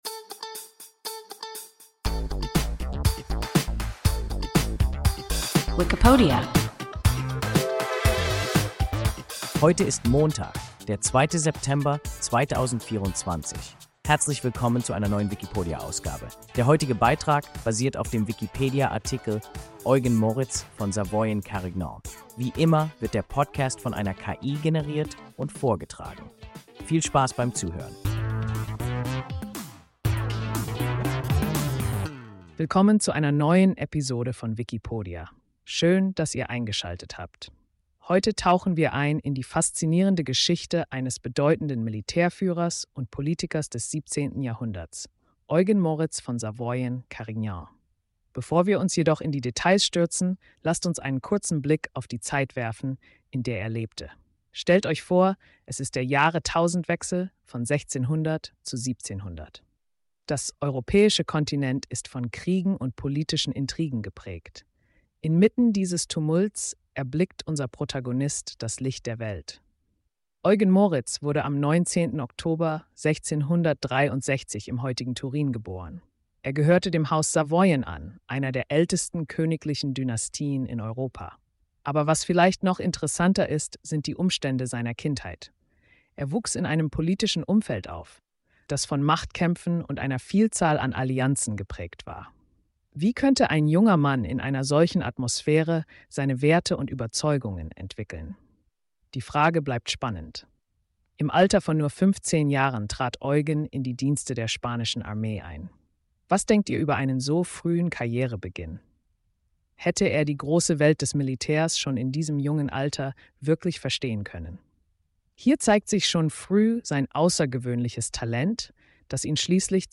Eugen Moritz von Savoyen-Carignan – WIKIPODIA – ein KI Podcast